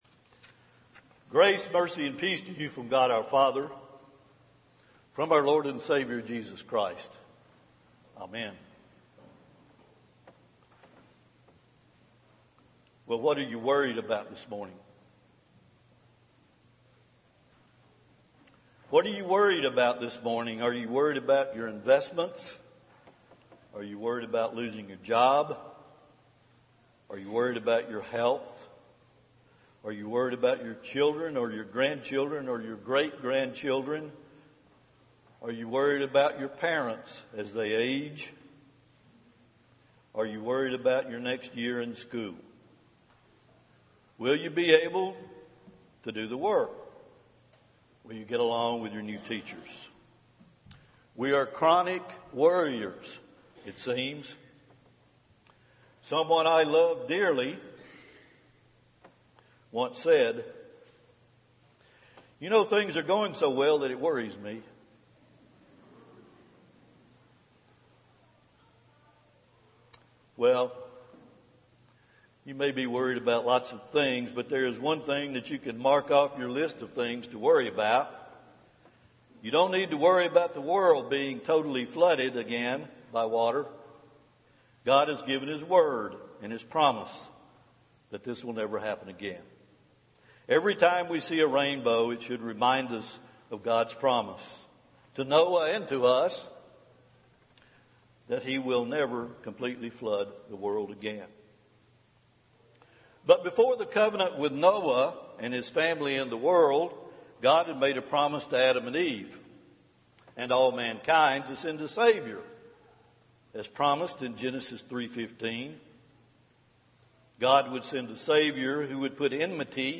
Home › Sermons › God Is Always Faithful to His Promises